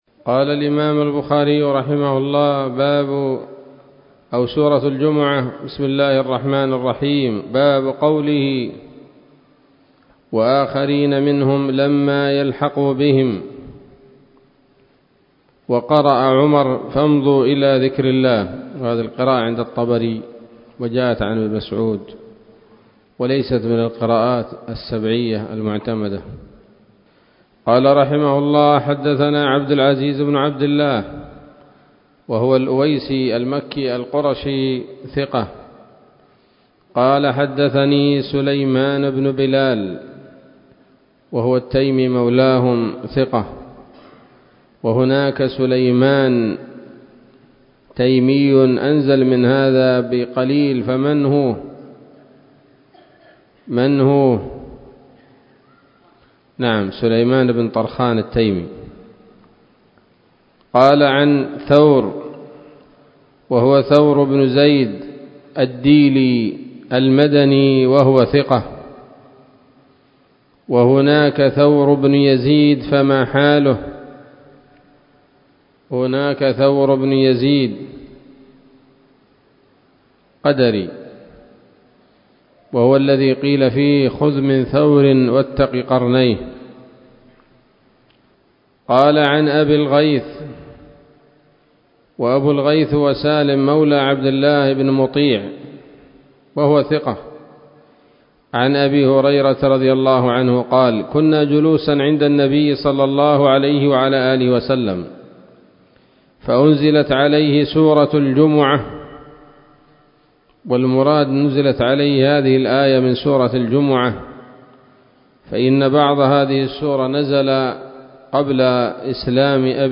الدرس الستون بعد المائتين من كتاب التفسير من صحيح الإمام البخاري